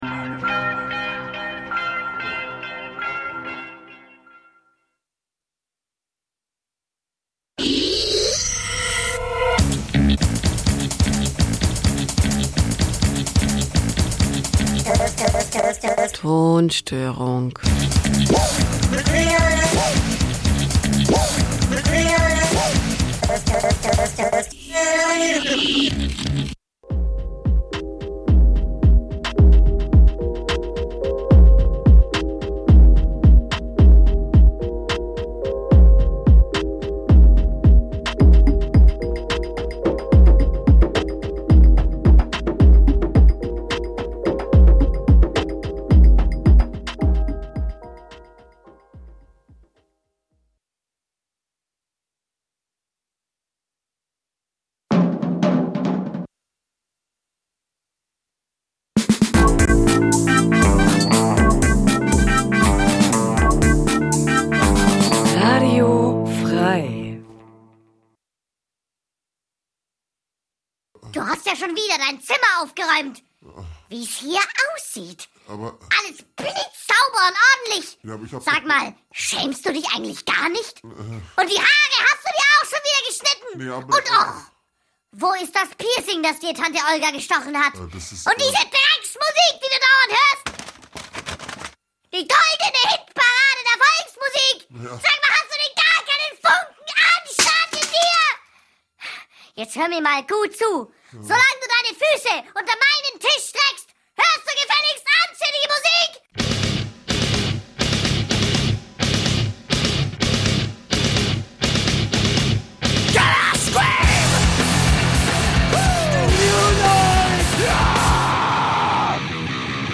...25 Jahre of total Krach... die beste Mischung aus genialen Neuvorstellungen und unerl�sslichen Konzerttips... aus dem old school, Metal-, Rock 'n' Roll-, Grind- und Hardcore- und sonstigen "gute Musik"- Bereichen...